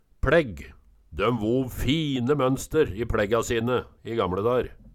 pLegg - Numedalsmål (en-US)